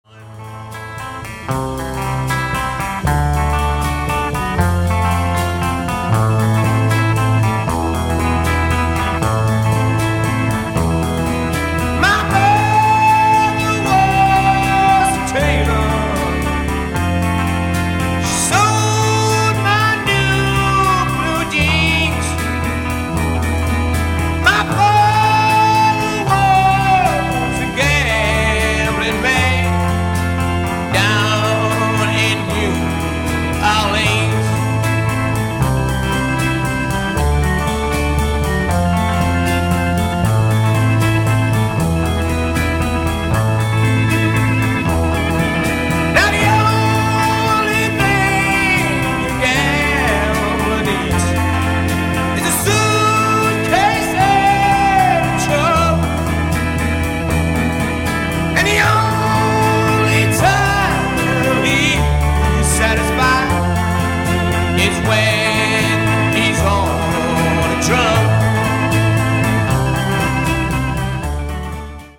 minor key arpeggio
organ